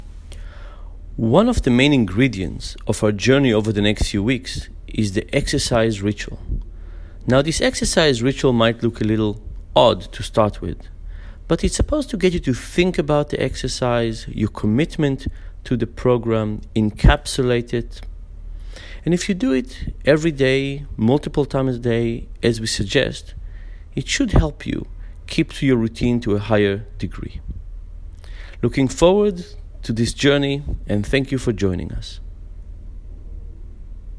Dan Ariely, der berühmten Verhaltensforscher und Fabulous-Berater hat an dieser Stelle eine Botschaft für dich: